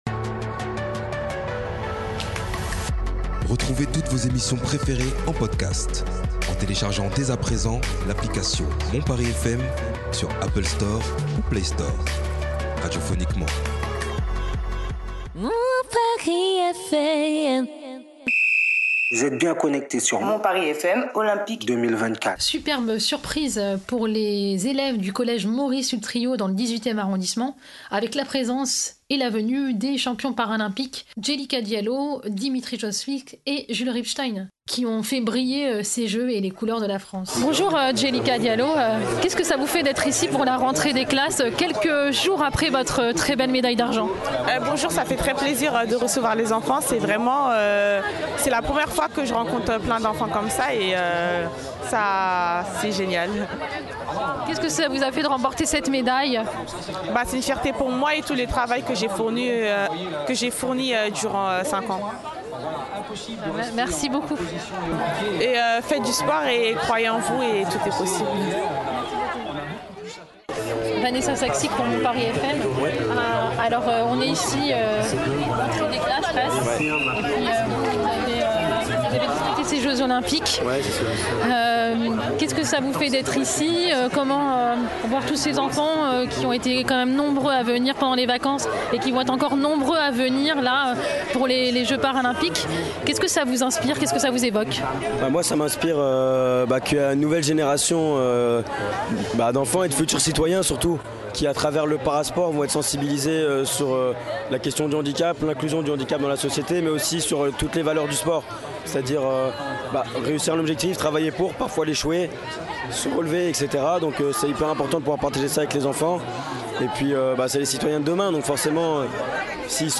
Au micro de Mon Paris FM, Tony Estanguet, Président de Paris 2024, Amélie Oudéa Castéra, Ministre des Sports et des JOP de Paris 2024 et Nicole Belloubet, Ministre de l’Education Nationale.